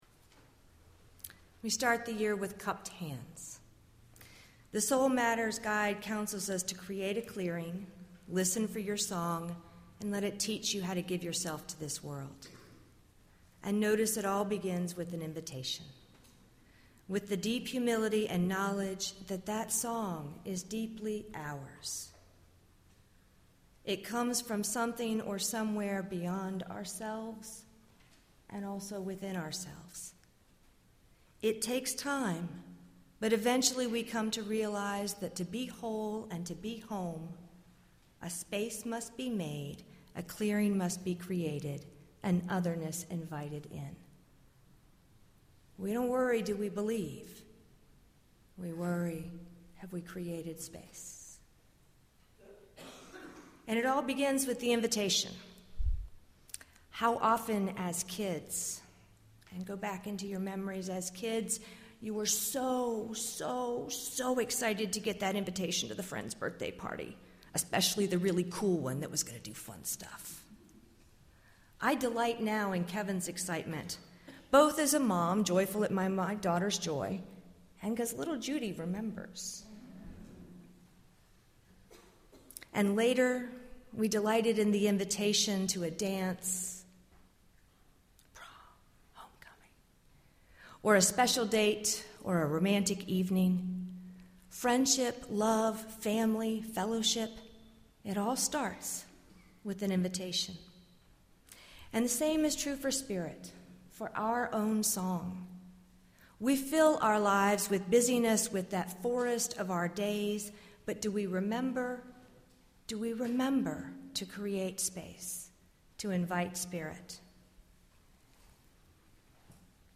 As we gather for our homecoming service to start our new church year, let us explore the notions of invitation and home.